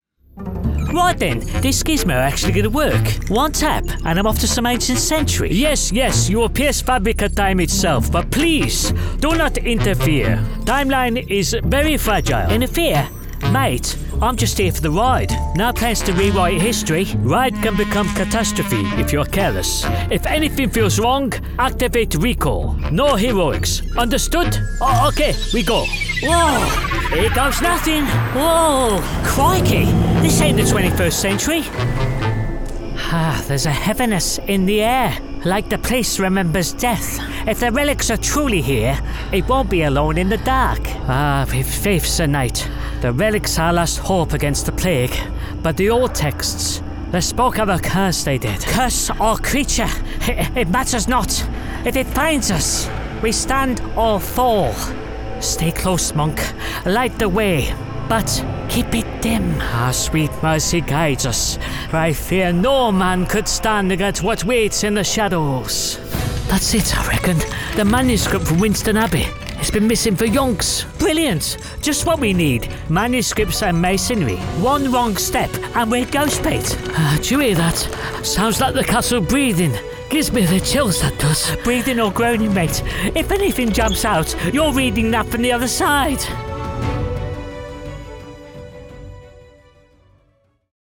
A dynamic British male voice. Positive, friendly and sincere with subtle Midlands tones.
Video Games
1106Gaming_Reel.mp3